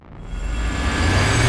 swoosh-up.wav